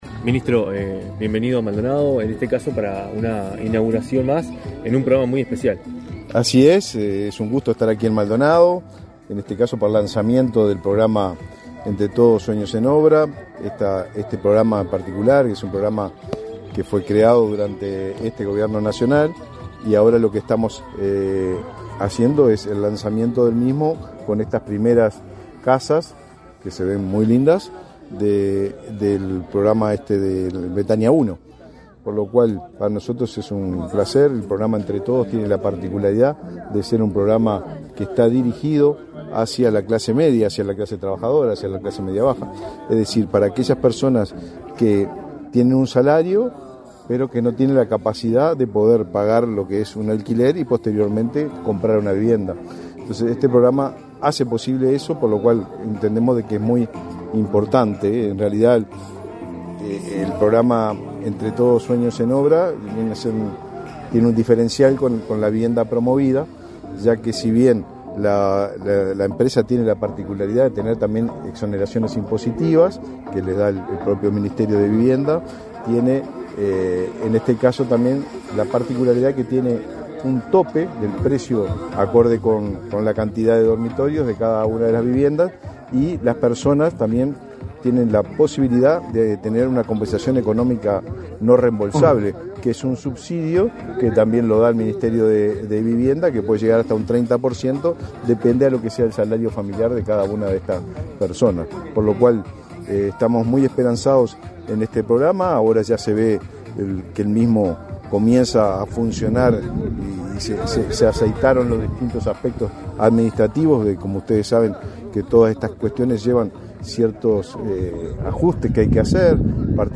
Declaraciones a la prensa del ministro del MVOT, Raúl Lozano
Tras el evento, el ministro Lozano realizó declaraciones a la prensa.